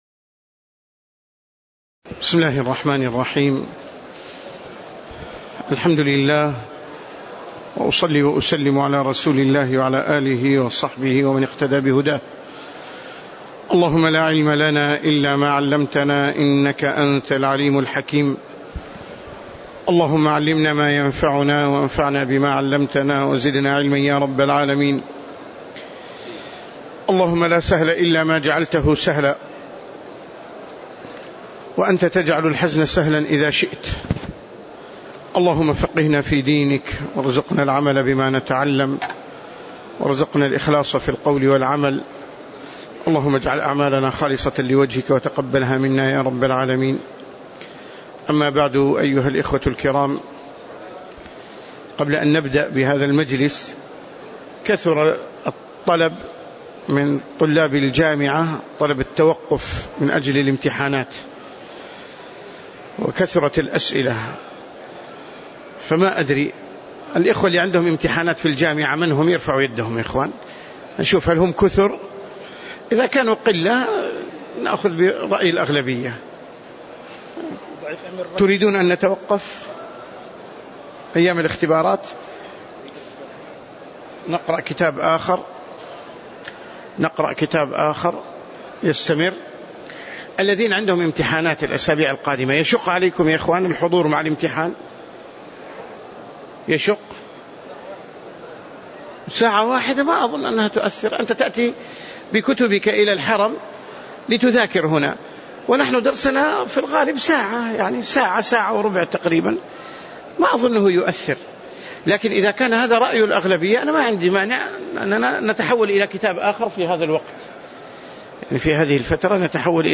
تاريخ النشر ٦ ربيع الثاني ١٤٣٨ هـ المكان: المسجد النبوي الشيخ